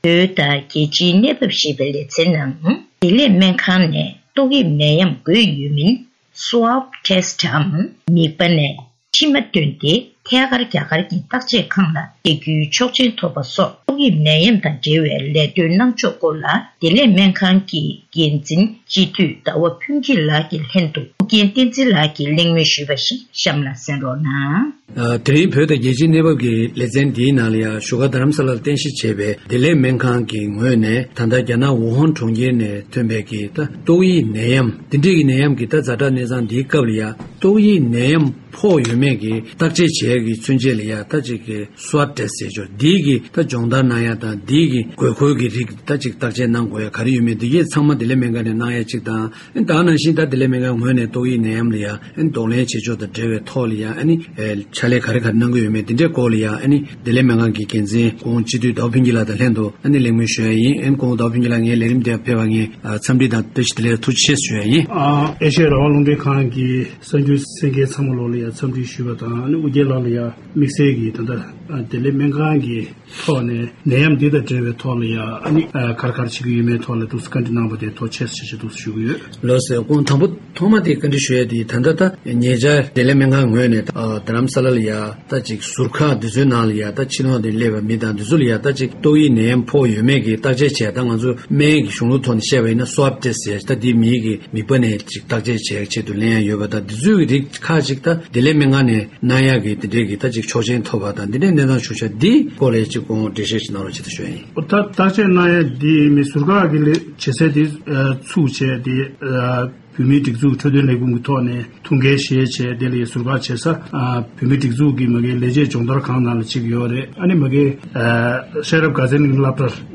གནས་འདྲི་ཞུས་པའི་ལེ་ཚན་ལ་གསན་རོགས།།